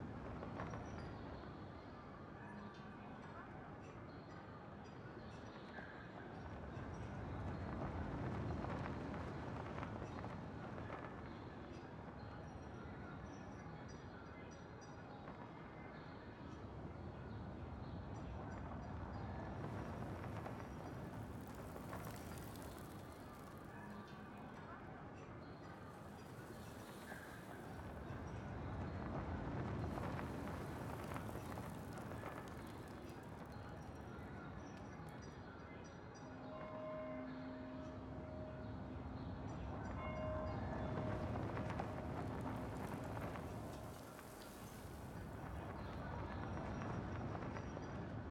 sfx-perks-prec-primary-amb.ogg